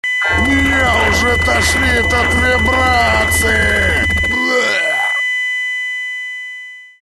• Пример реалтона содержит искажения (писк).